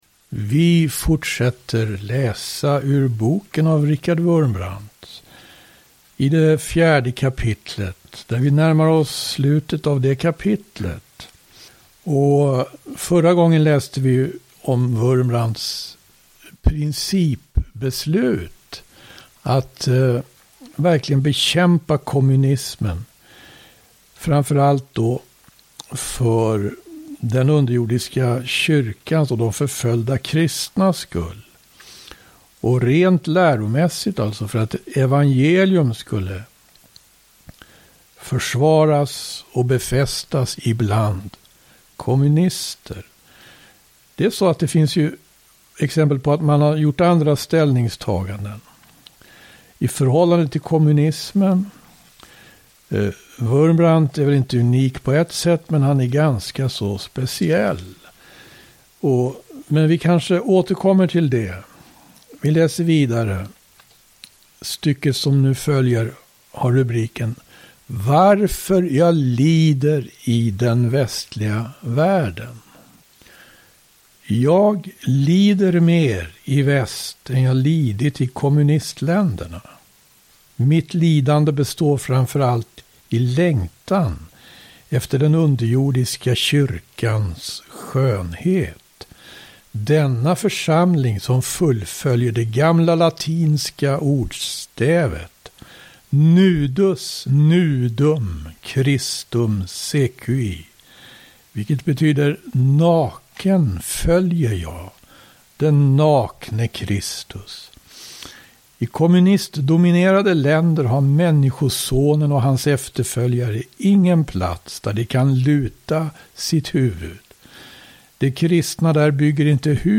läser ur boken Torterad för Kristi skull av Richard Wurmbrand.